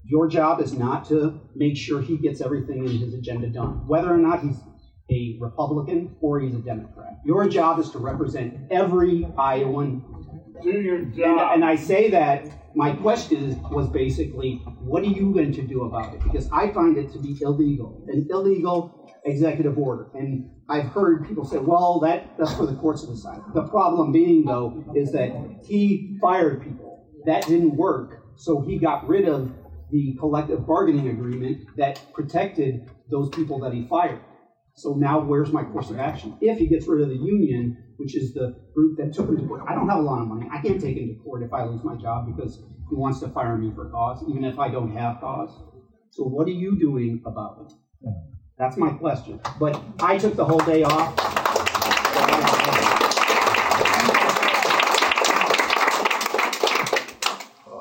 U.S. Senator Chuck Grassley faced pointed and emotional questions from constituents during a recent town hall in southeast Iowa, as attendees raised concerns about rural health care, veteran services, environmental oversight, and the future of key social safety net programs.